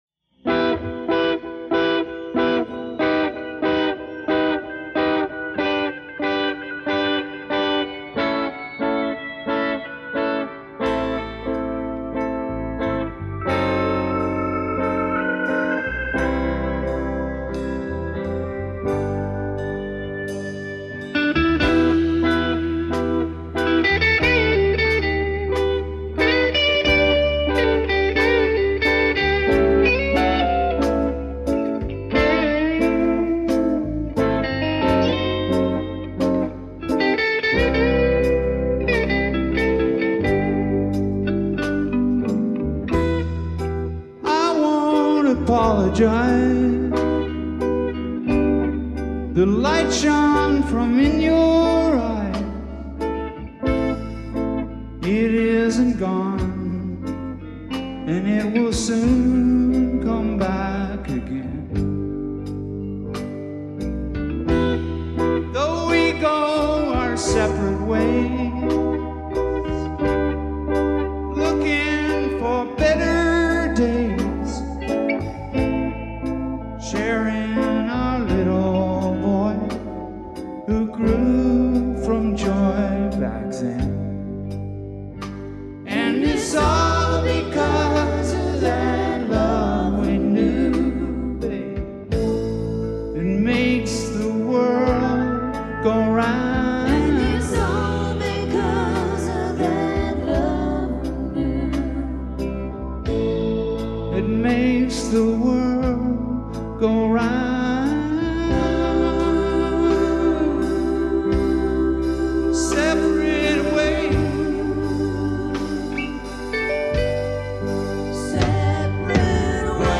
from Roskilde 1993